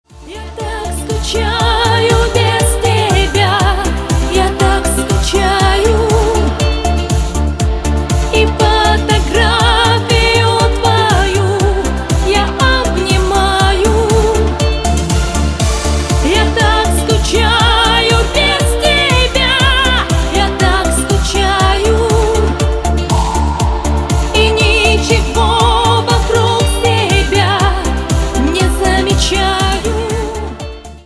поп
женский вокал